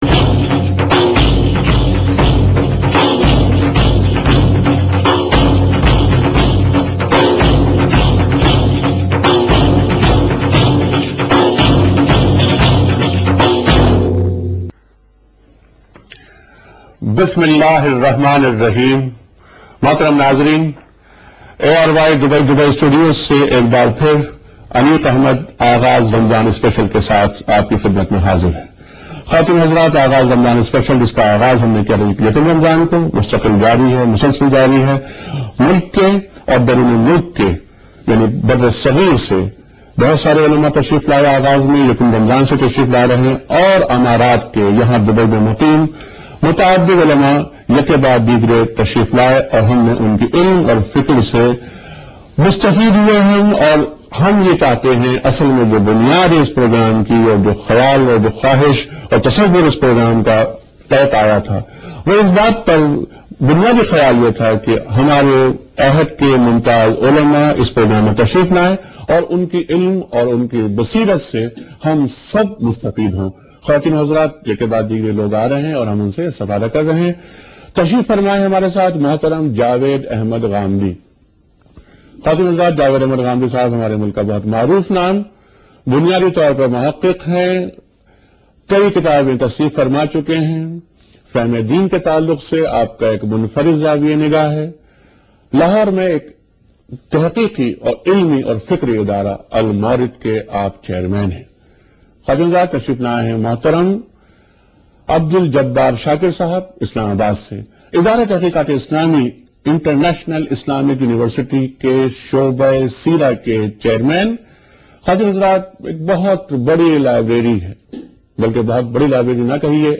Category: TV Programs / ARY /